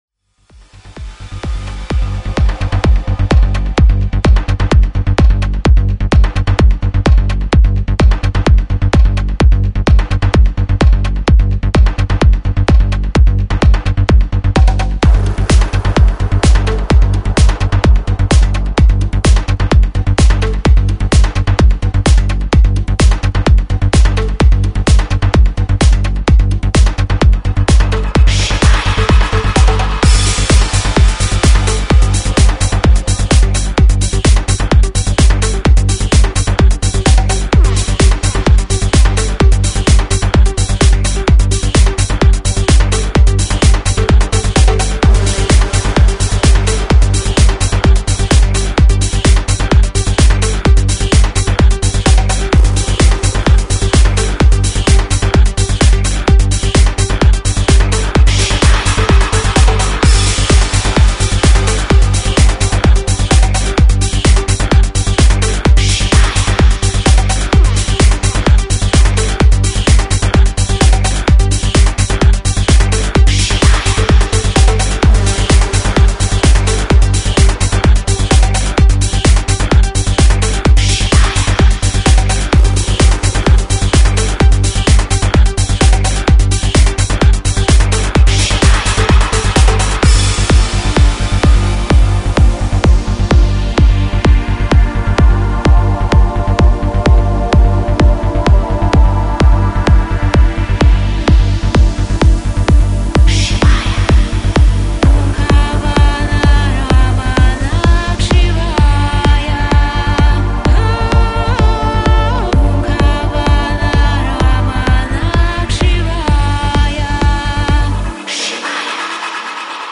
Progressive House / Trance